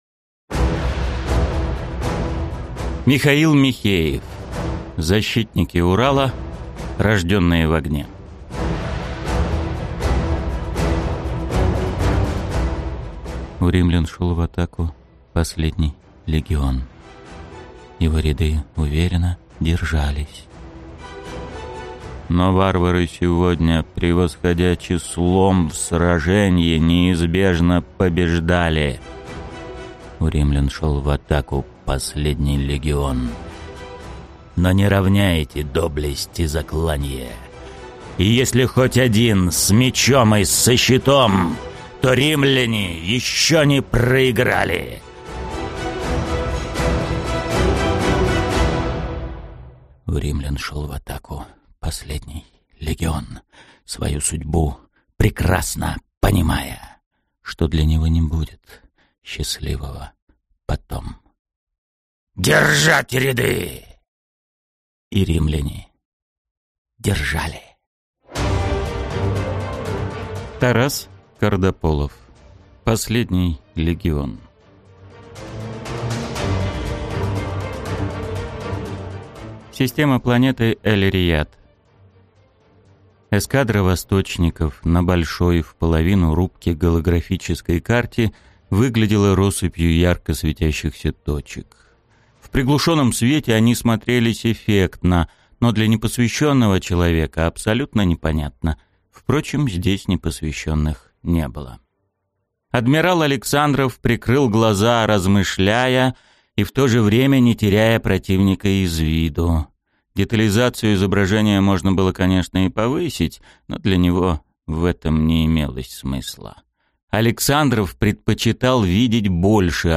Аудиокнига Рожденные в огне | Библиотека аудиокниг
Прослушать и бесплатно скачать фрагмент аудиокниги